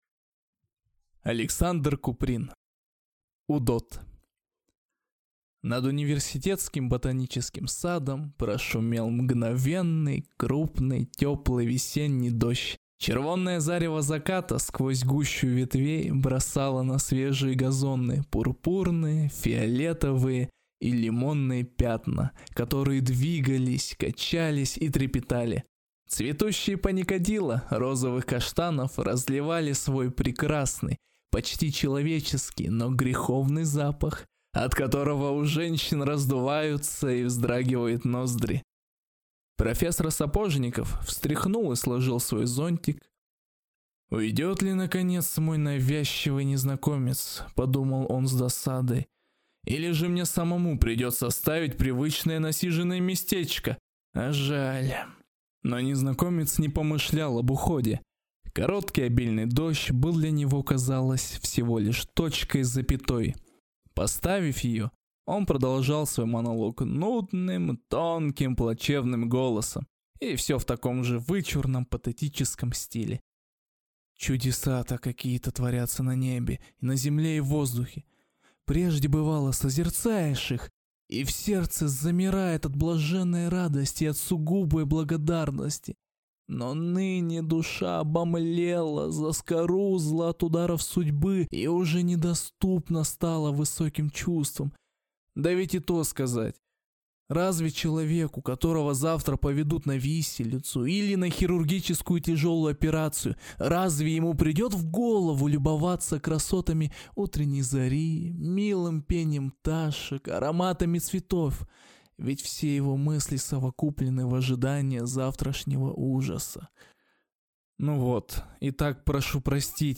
Аудиокнига Удод | Библиотека аудиокниг